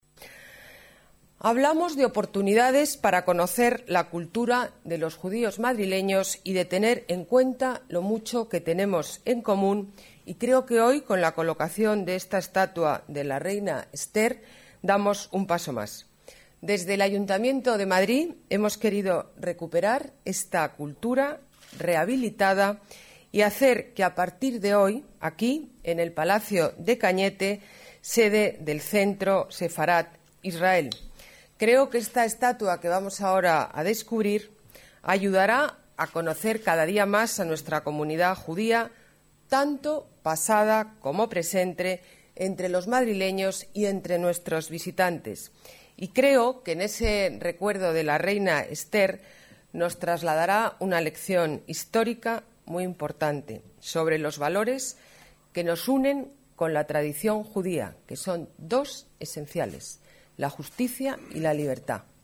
Nueva ventana:Declaraciones de la alcaldesa Ana Botella durante la colocación de la escultura de la Reina Esther